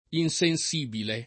[ in S en S& bile ]